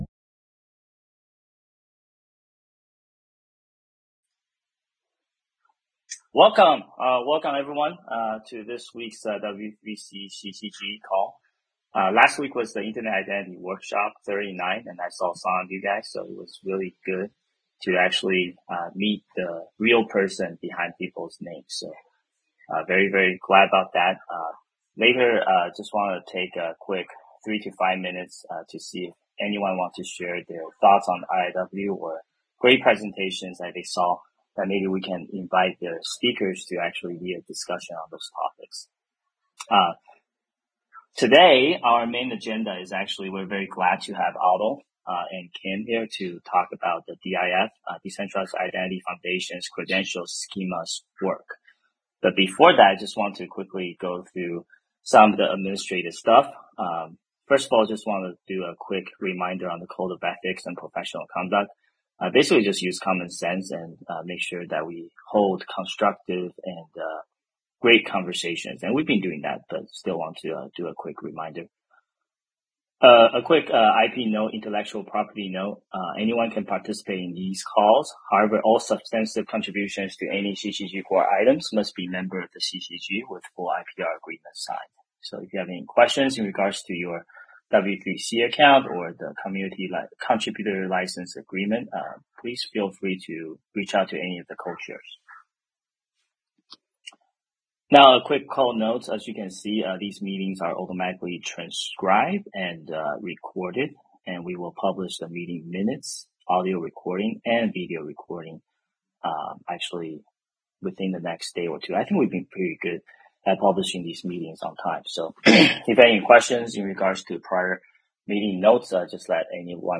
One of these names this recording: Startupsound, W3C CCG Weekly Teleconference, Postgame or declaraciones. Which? W3C CCG Weekly Teleconference